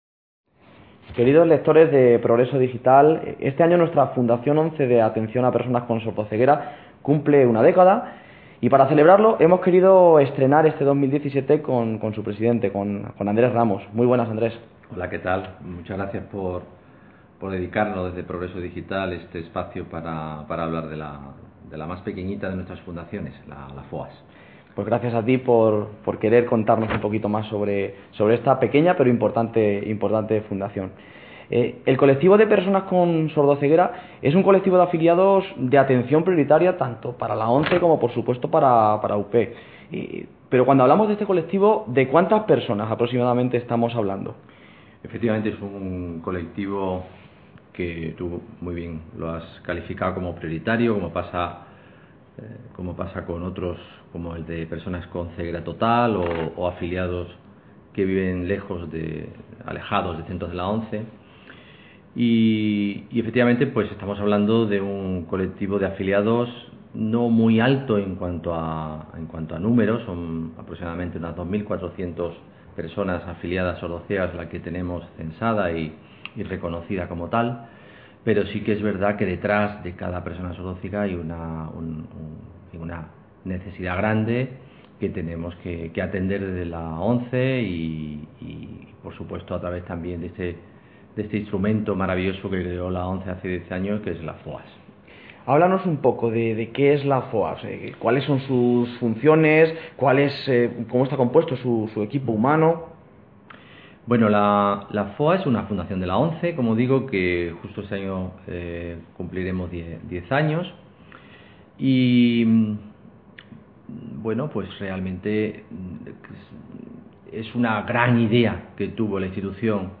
En esta entrevista sonora